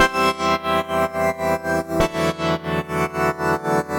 Index of /musicradar/sidechained-samples/120bpm
GnS_Pad-MiscB1:8_120-E.wav